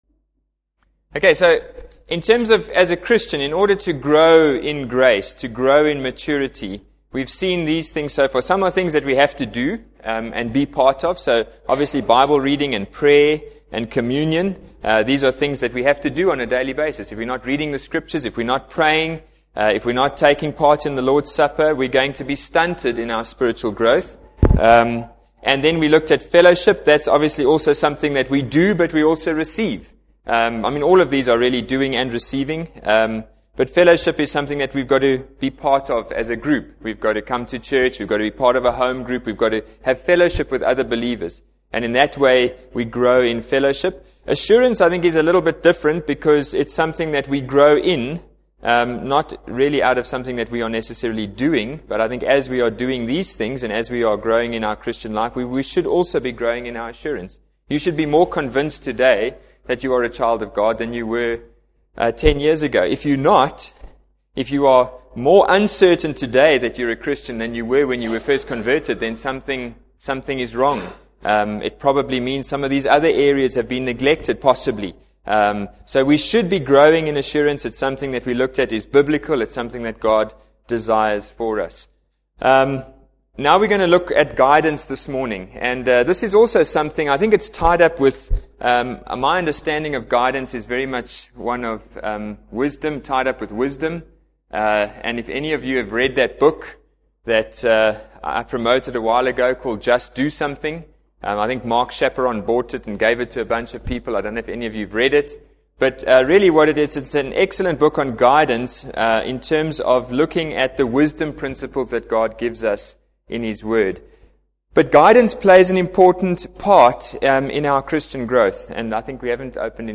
Adult Bible Class  - Growing in Grace - 4.mp3